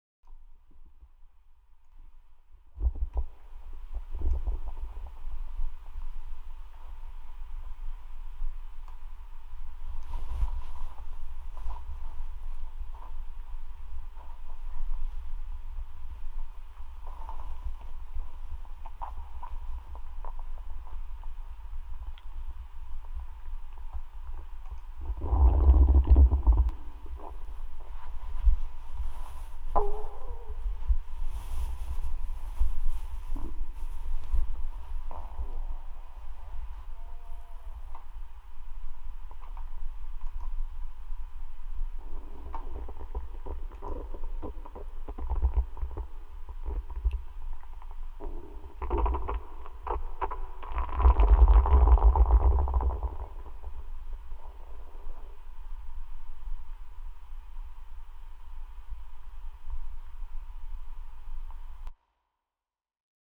(bon sinon, j'ai un bon micro, et j'ai bourrine sur le gain...)
gargouille.mp3